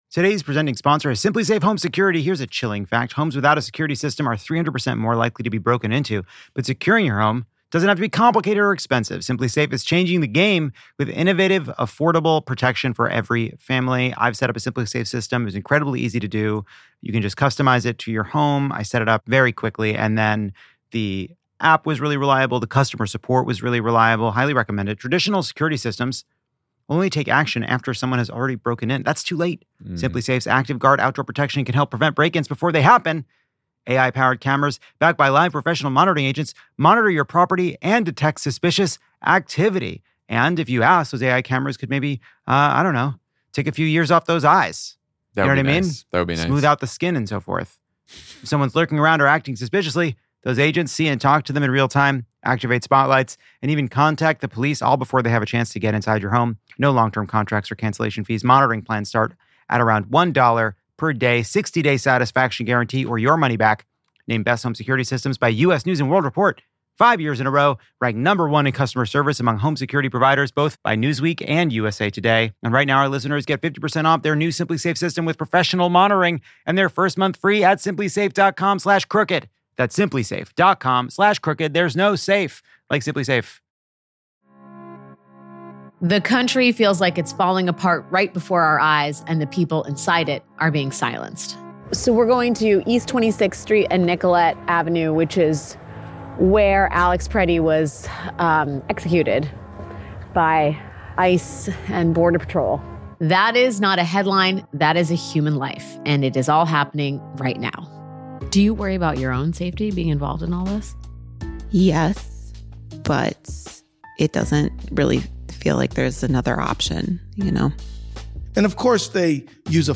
Live from Sydney, Jon, Lovett, Tommy, and Dan discuss the lurid details of The Wall Street Journal exposé on Kristi Noem and Corey Lewandowski's eye-opening behavior at (and high above) the Department of Homeland Security.